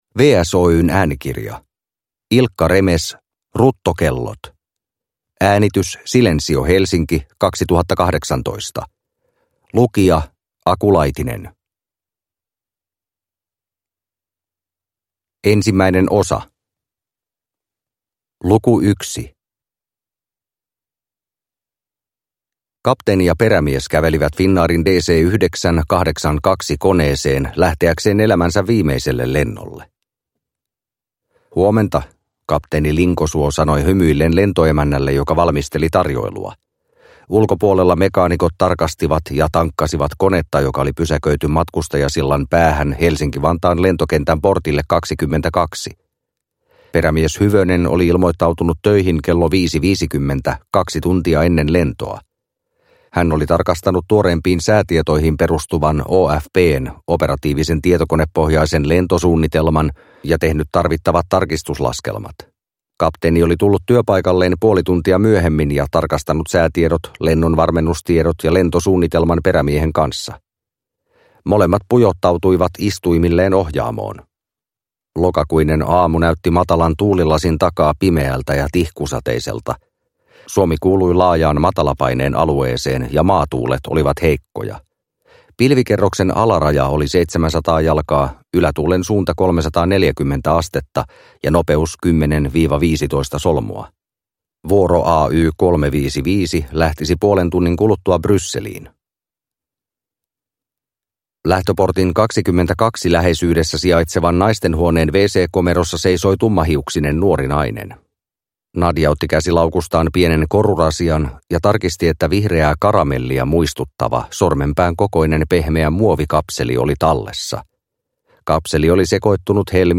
Ruttokellot – Ljudbok – Laddas ner